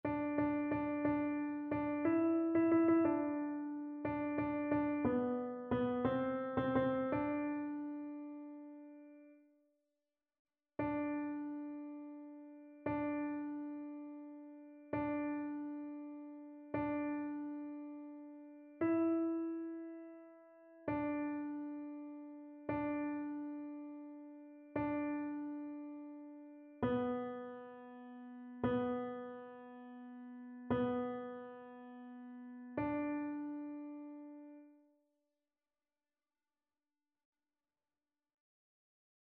Chœur
TénorBasse
annee-b-temps-ordinaire-saint-sacrement-psaume-115-tenor.mp3